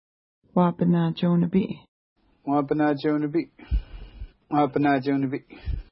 Pronunciation: wa:pəna:tʃew-nəpi:
Pronunciation